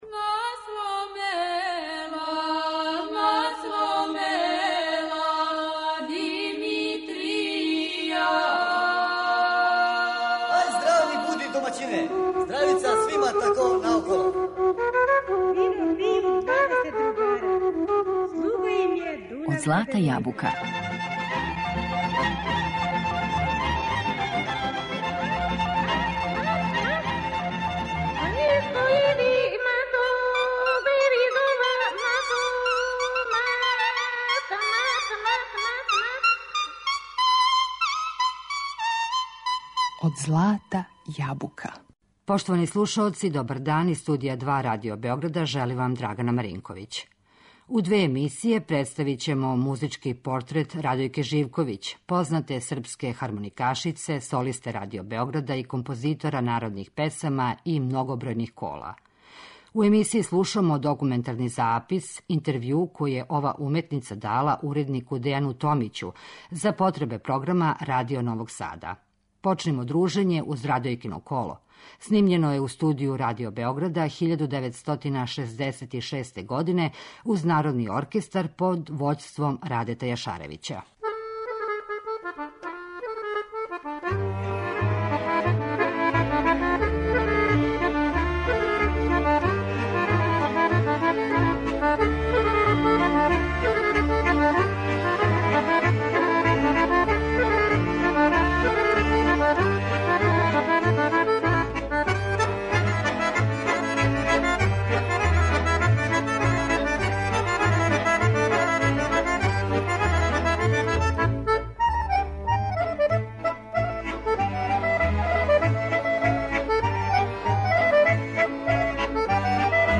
Mузички портрет
познате српске хармоникашице